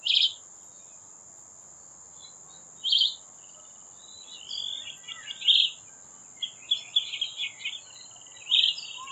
Chivi Vireo (Vireo chivi)
Detailed location: Reserva Ecológica Ciudad Universitaria UNL (RECU)
Condition: Wild
Certainty: Photographed, Recorded vocal